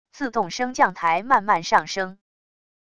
自动升降台慢慢上升wav音频